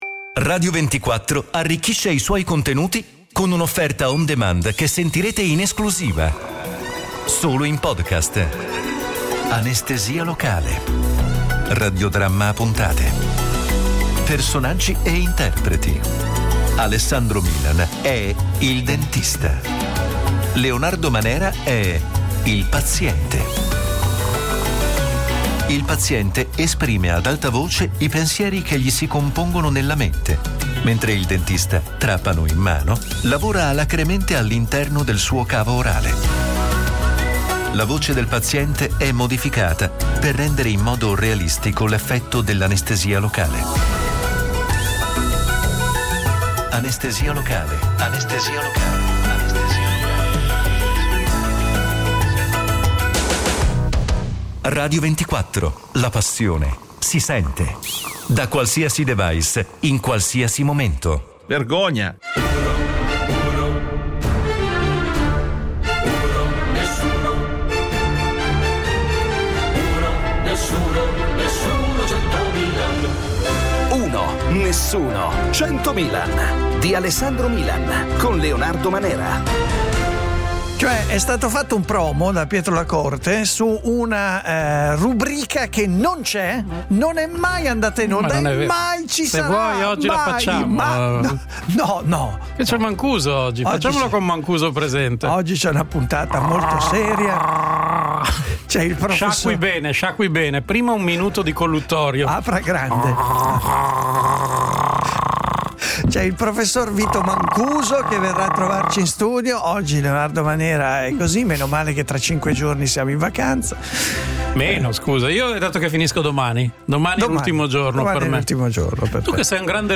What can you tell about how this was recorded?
This is a recording of an X Space